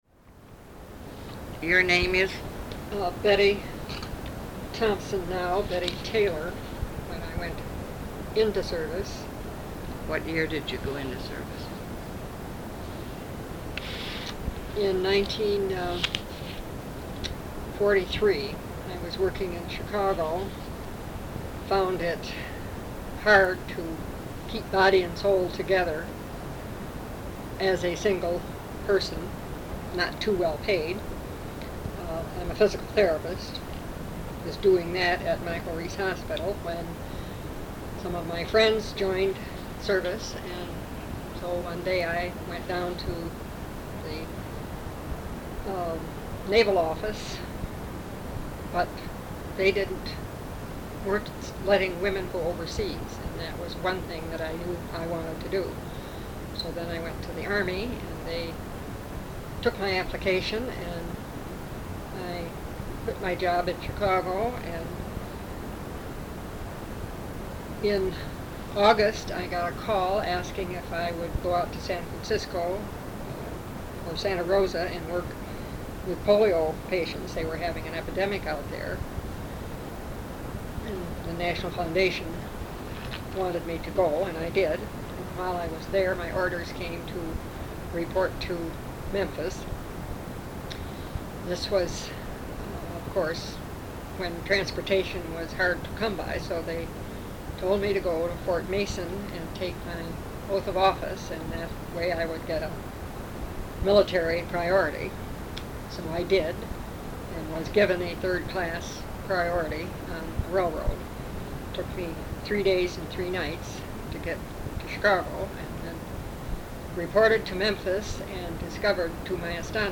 Women's Overseas Service League Oral History Project